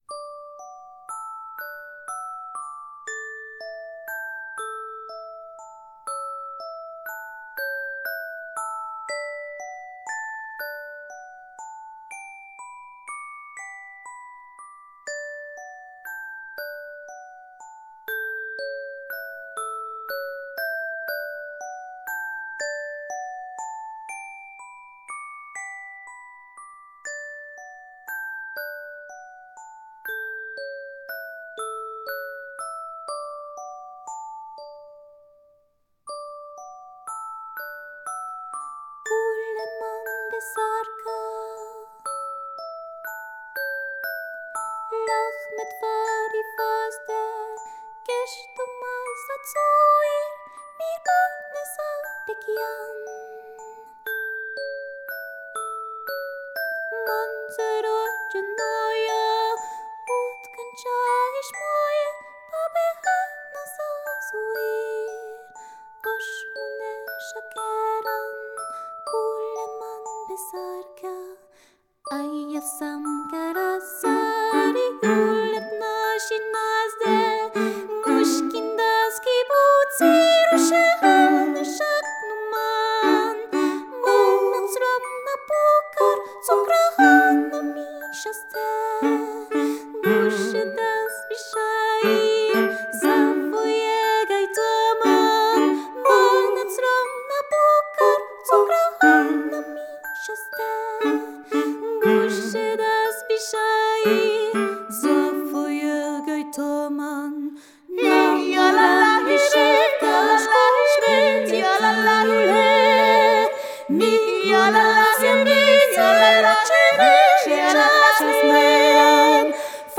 Percussions